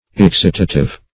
Excitative \Ex*cit"a*tive\, a. [Cf. F. excitatif.]